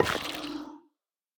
Minecraft Version Minecraft Version snapshot Latest Release | Latest Snapshot snapshot / assets / minecraft / sounds / block / sculk_shrieker / place3.ogg Compare With Compare With Latest Release | Latest Snapshot